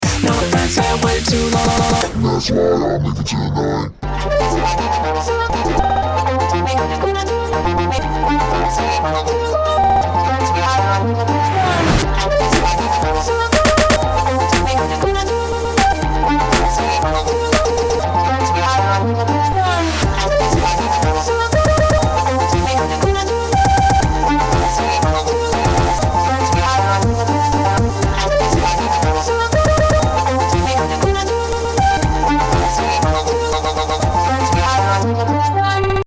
MIDIキーボードの鍵盤やツマミをいじるだけでスクラッチ音や一部分の音のループ、または徐々に音が遅くなっていくなどのエフェクトを操作できてしまいます。
EDM系